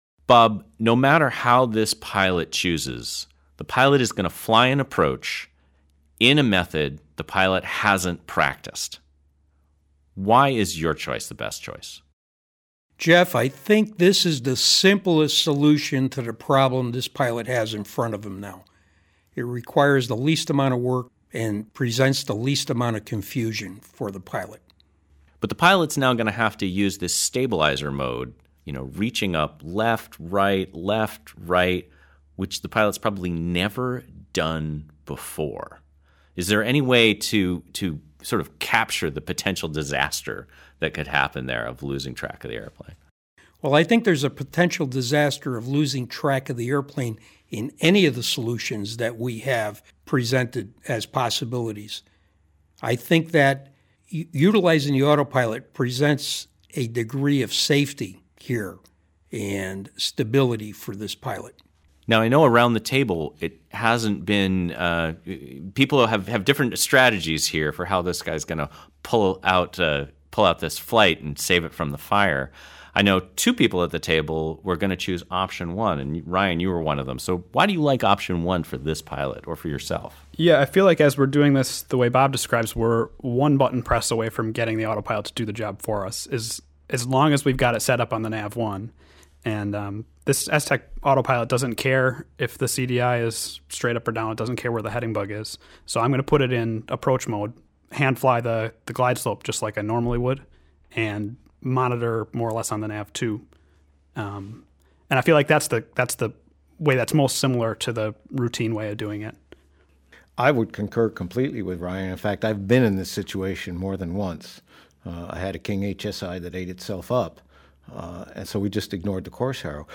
What_can_you_trust_roundtable.mp3